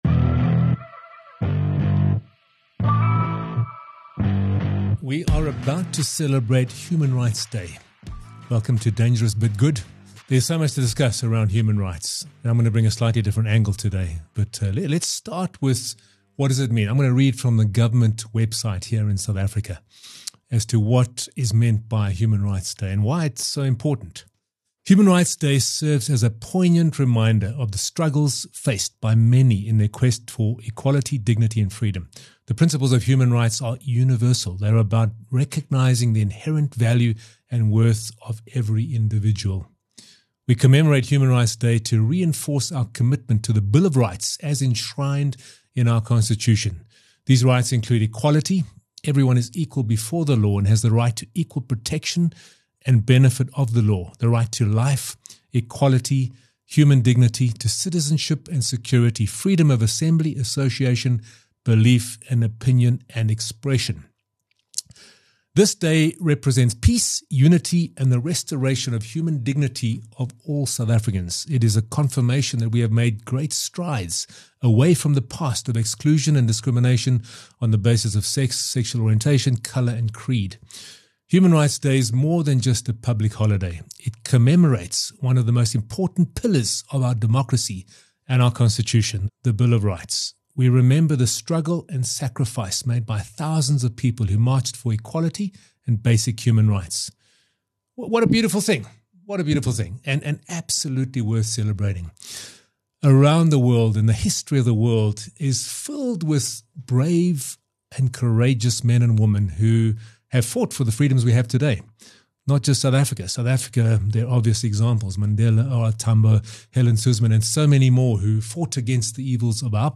Tune in for a compelling conversation that challenges the way we think about rights, freedom, and accountability.